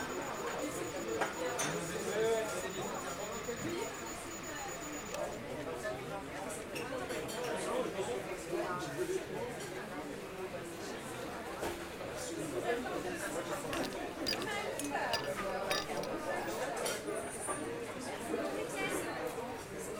Ambiance Café de Paris (Broadcast) – Le Studio JeeeP Prod
Bruits d’ambiance dans une salle de café.
Ambiance-Cafe-de-Paris.mp3